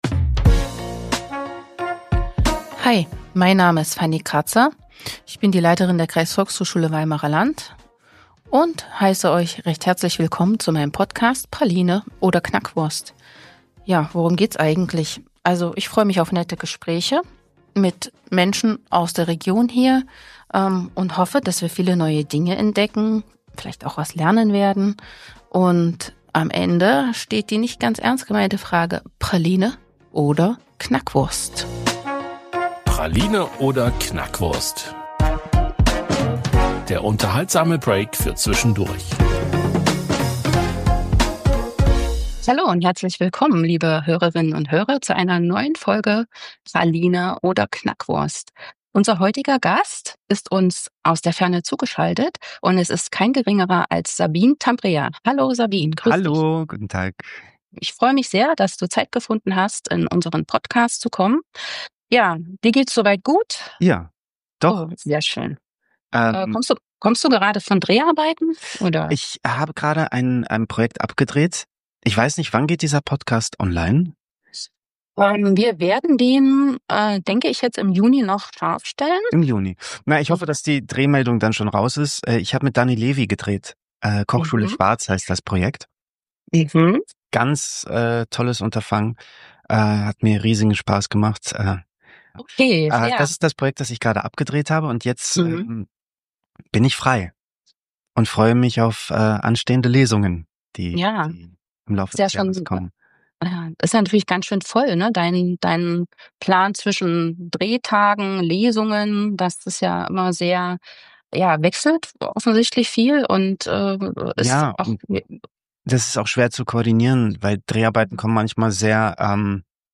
In dieser eindrücklichen Folge spricht Schauspieler, Musiker und Autor Sabin Tambrea über den Klang der Kindheit in einer Diktatur, über Migration und das Ankommen in einer neuen Welt – und über die leise, aber nachhaltige Kraft der Kunst. Ausgangspunkt des Gesprächs ist sein neues Buch „Vaterländer“, ein autofiktionaler Roman über Familie, Flucht und die Frage, was Heimat eigentlich bedeutet.
Ein persönliches, kluges und bewegendes Gespräch über Herkunft und Haltung.